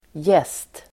Uttal: [jes:t]